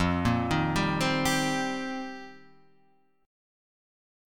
F9sus4 chord {1 1 1 0 1 3} chord